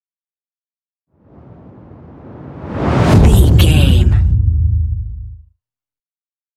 Dramatic whoosh to hit deep trailer
Sound Effects
Fast paced
Atonal
dark
intense
tension
woosh to hit